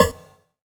SNARE 25  -R.wav